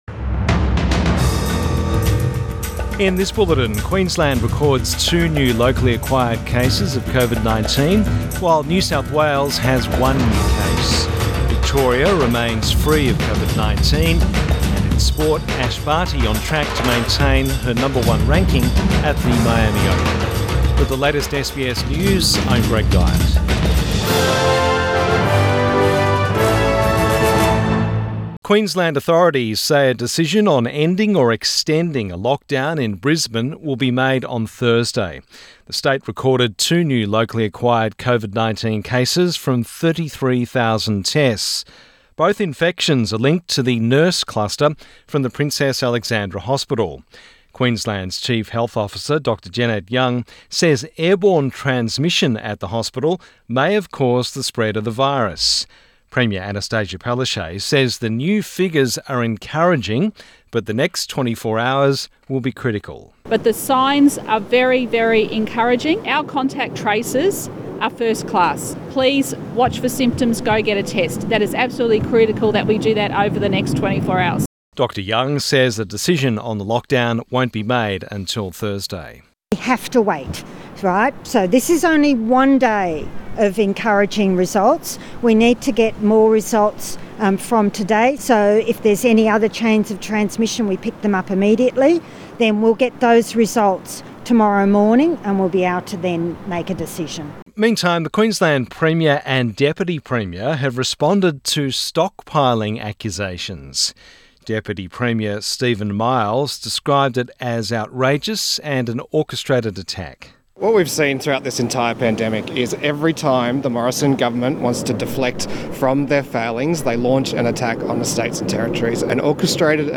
Midday bulletin 31 March 2021